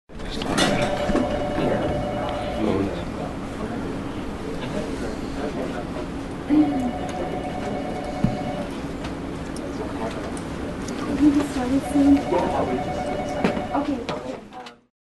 zvuk-ofisa_007
zvuk-ofisa_007.mp3